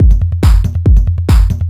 DS 140-BPM A5.wav